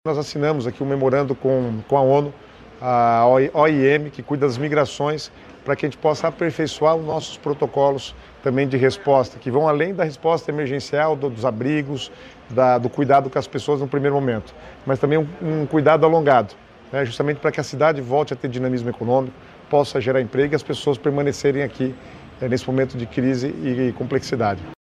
Sonora do secretário das Cidades, Guto Silva, sobre a parceria com a ONU para protocolos de desastres